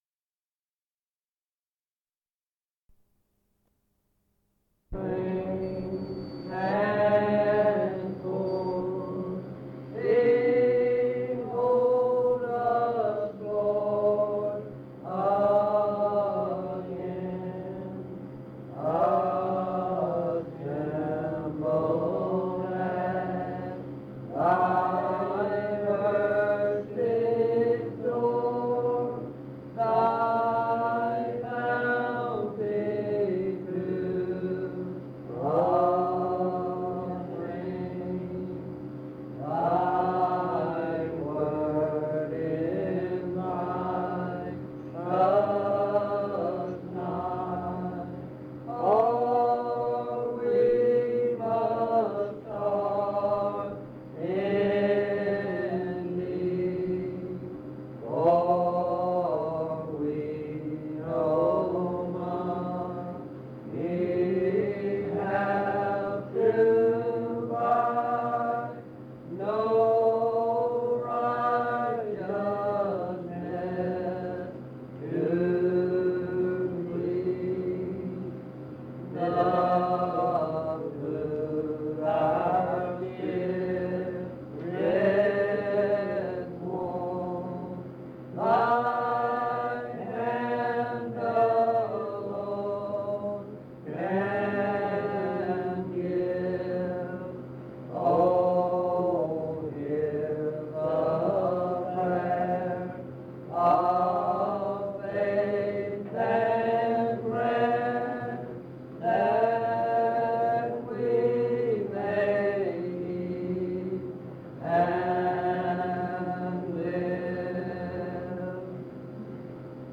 "Communion service" - Monticello Primitive Baptist Church